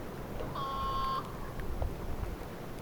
satamavarisparin, koiraan, soidinääni
Satamassa ja sen läheisyydessä talvehtii
Ja tässä sen koiraan soidinääni.
satamavarisparin_koiraan_soidinaani.mp3